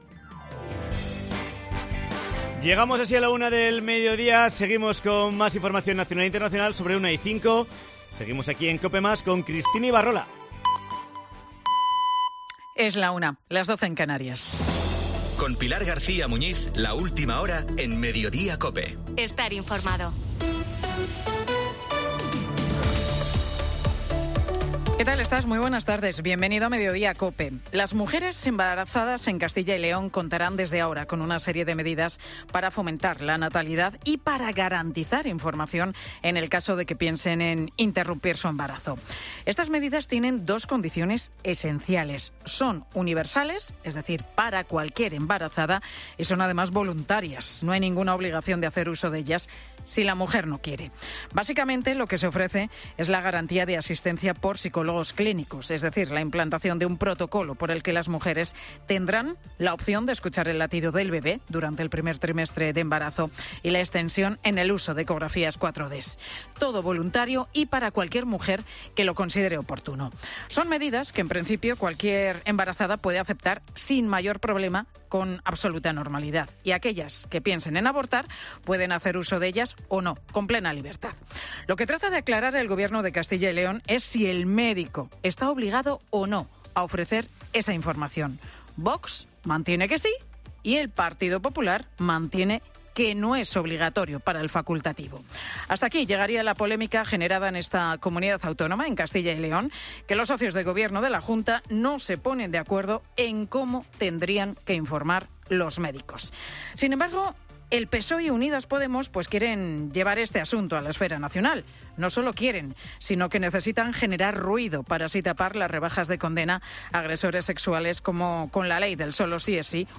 Entrevista en COPE Navarra a Cristina Ibarrola, candidata de UPN a la alcaldía de Pamplona